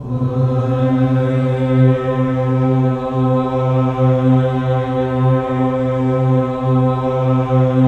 VOWEL MV08-L.wav